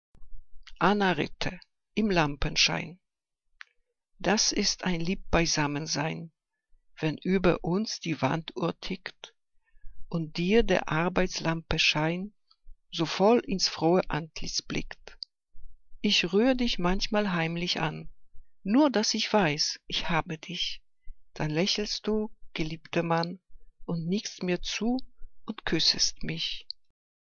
Liebeslyrik deutscher Dichter und Dichterinnen - gesprochen (Anna Ritter)
Ausgewählte Liebesgedichte